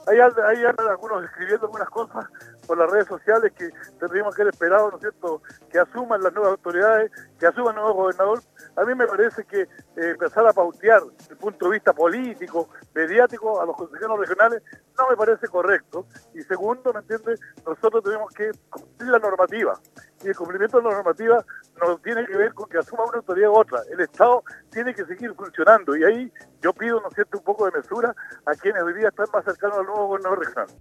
En conversación con radio Sago, indicó que luego que el Consejo ampliara la postulación del 6% del FNDR, recibieron críticas de cercanos a Patricio Vallespín, electo gobernador regional, en el sentido de que gran parte de estos proyectos deben estar paralizados hasta que asuma la nueva autoridad.